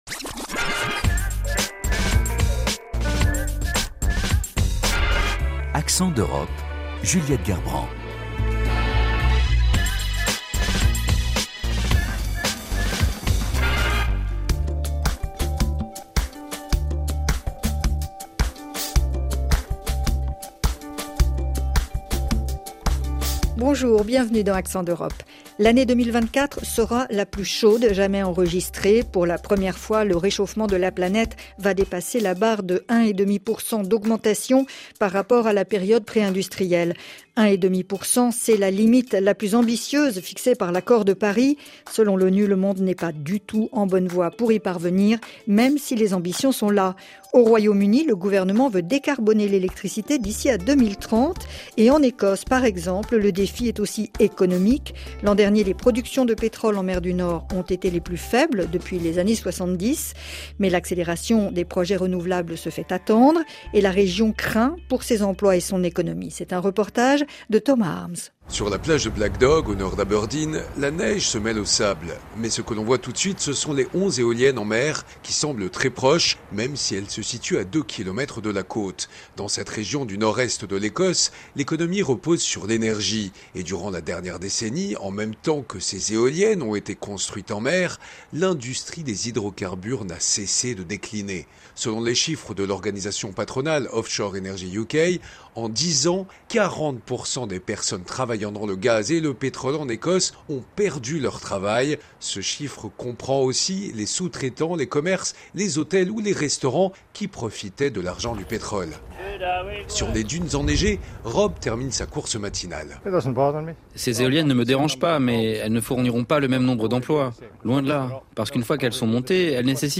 Et entretien avec Kate Forbes, vice-Première ministre de l’Écosse, et ministre de l’Économie.